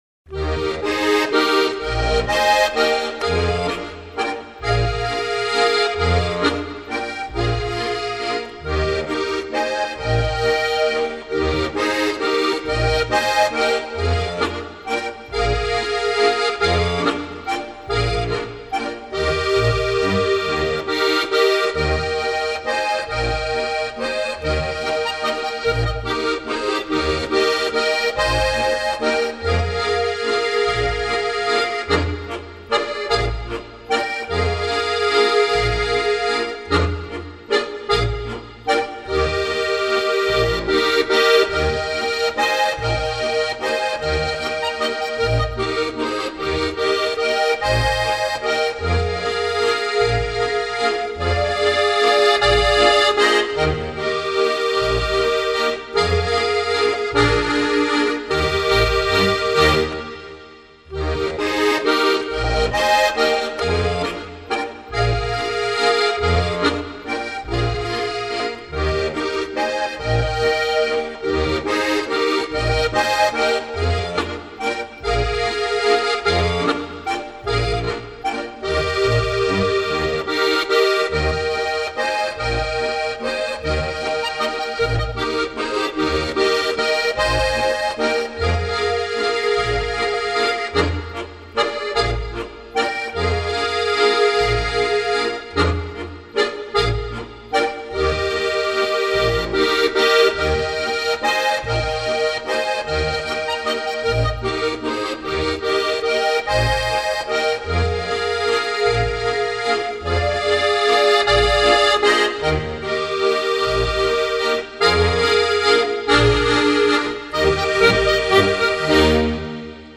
Volksmusik
Das Steirische Harmonika Ensemble der Musikschule Laabental